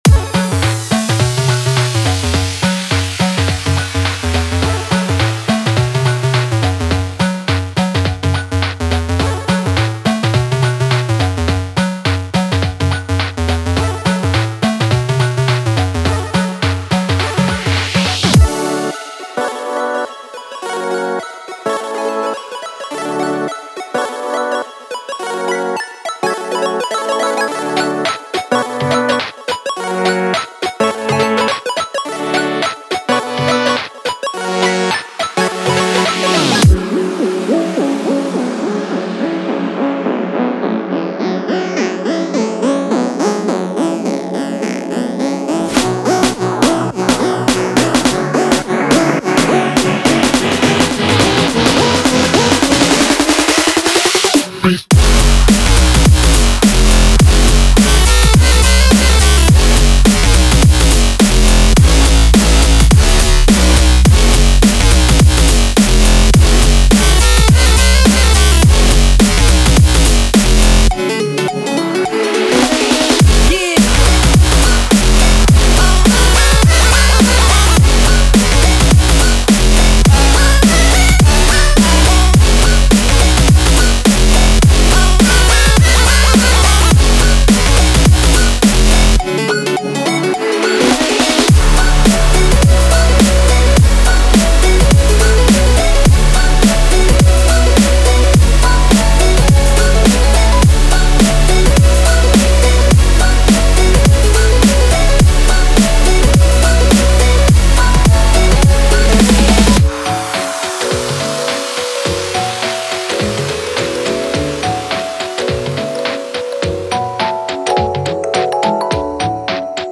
Melodic Dubstep, Weird, Quirky, Mysterious, Restless